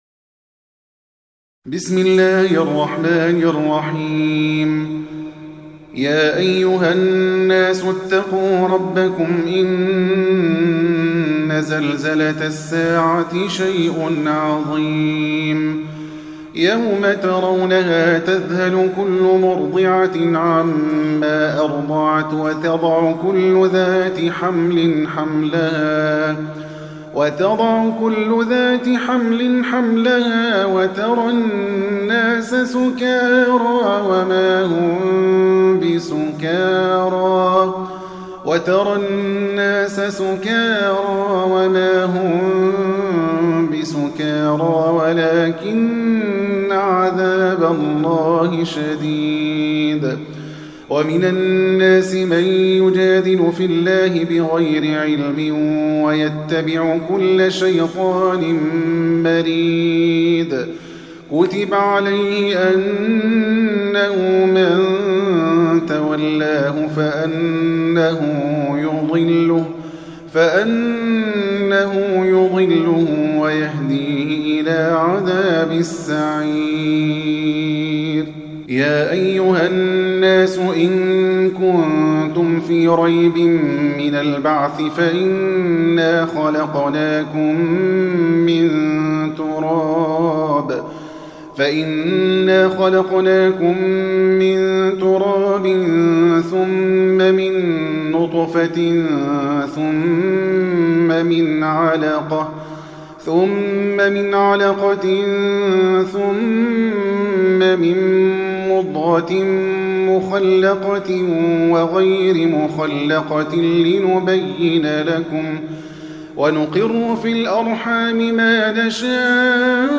Surah Sequence تتابع السورة Download Surah حمّل السورة Reciting Murattalah Audio for 22. Surah Al-Hajj سورة الحج N.B *Surah Includes Al-Basmalah Reciters Sequents تتابع التلاوات Reciters Repeats تكرار التلاوات